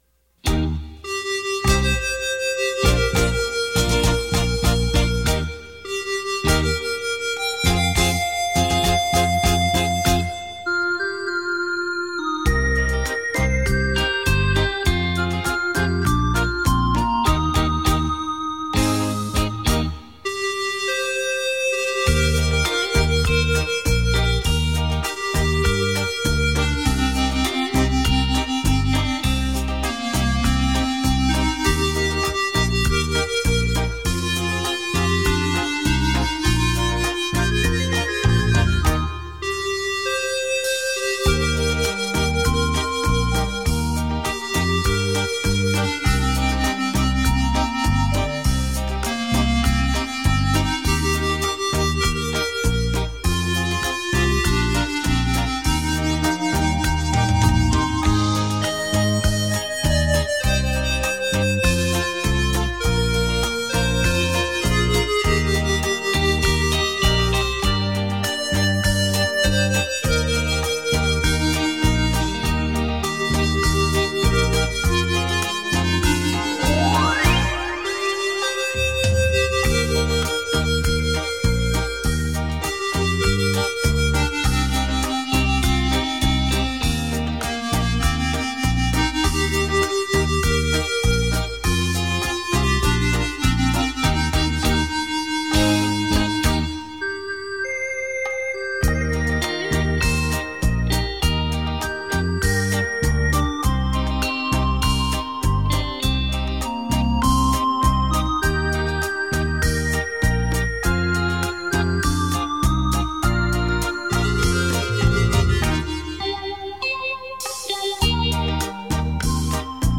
身历其境的临场效果